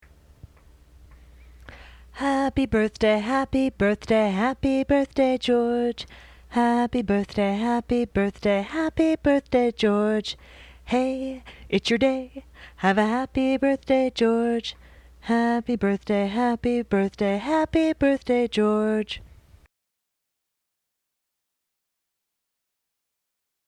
As you can tell from listening to them, I am not a professional singer. But what good is a Happy Birthday replacement if anyone can't just bust it out, with no accompaniment?